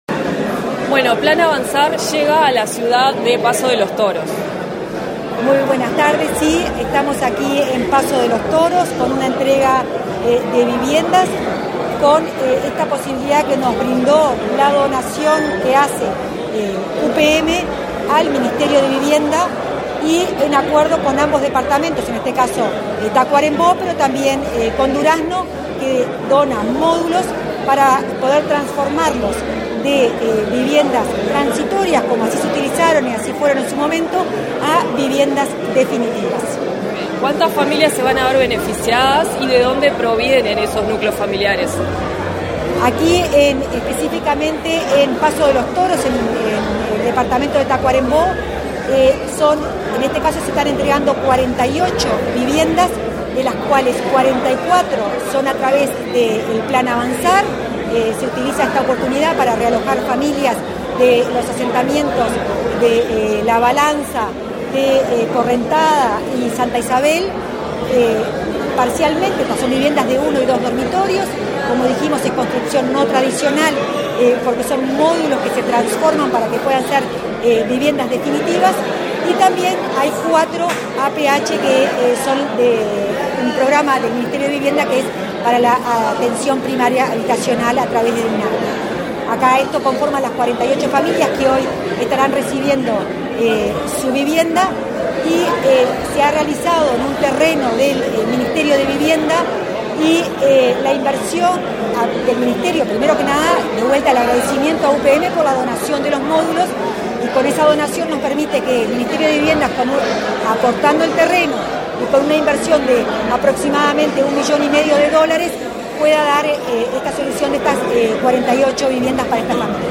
Entrevista a la directora de Integración Social y Urbana de la cartera, Florencia Arbeleche
El Ministerio de Vivienda y Ordenamiento Territorial (MVOT) realizó, este 5 de febrero, la entrega de 48 viviendas del Plan Avanzar para el realojo de familias de los barrios La Balanza, Santa Isabel y Correntada, en Paso de los Toros, departamento de Tacuarembó. Tras el evento, la directora de Integración Social y Urbana de la cartera, Florencia Arbeleche, realizó declaraciones a Comunicación Presidencial.